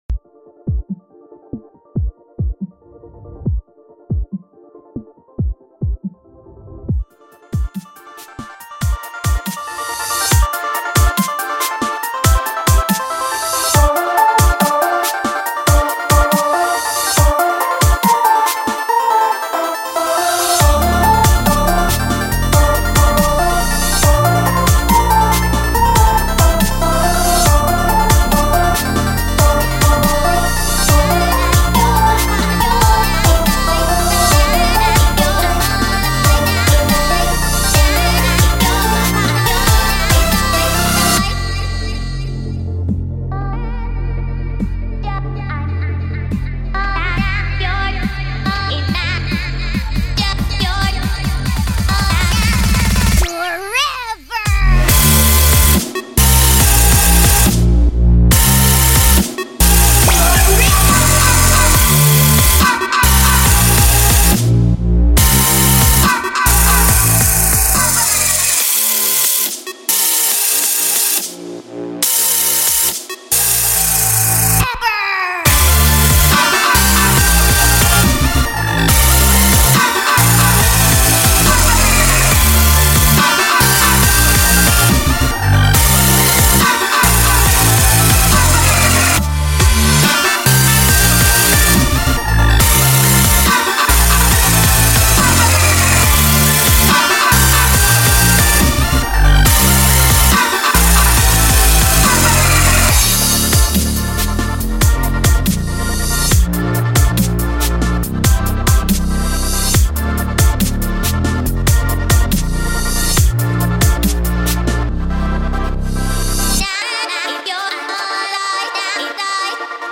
My first dubstep track.